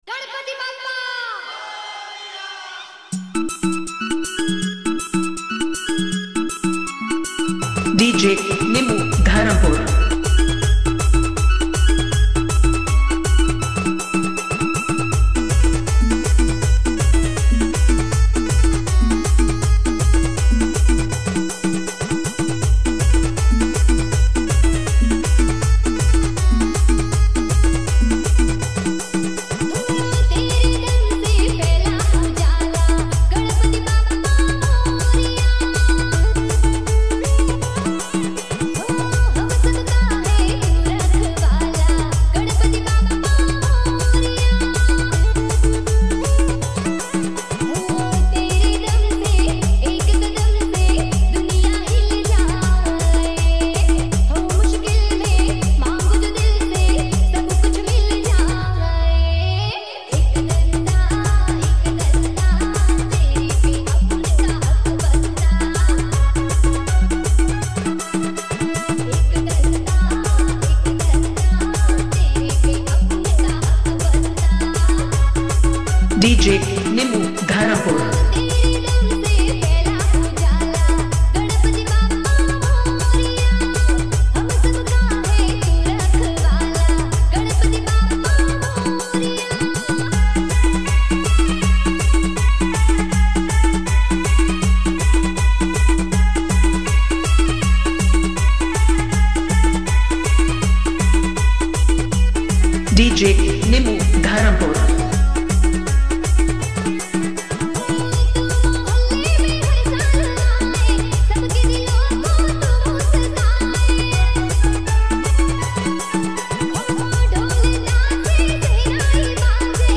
Dj Remix Gujarati